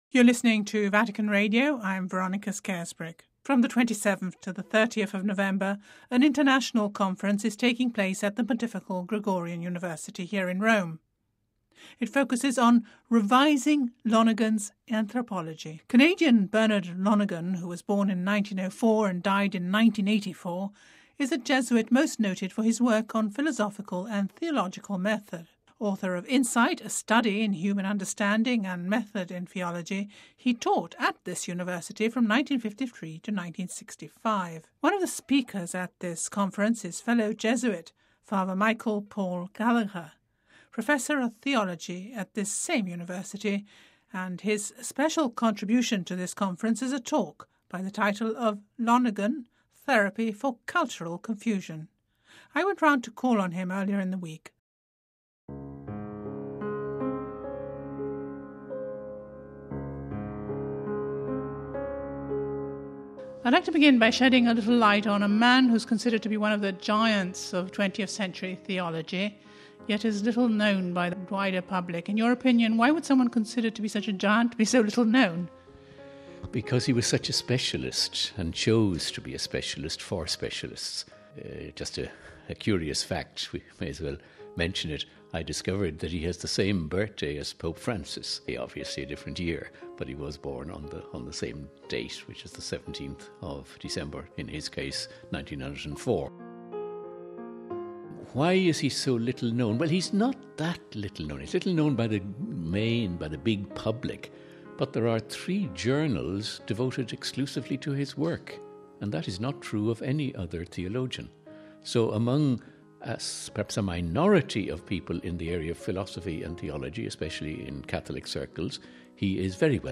This is what he told her: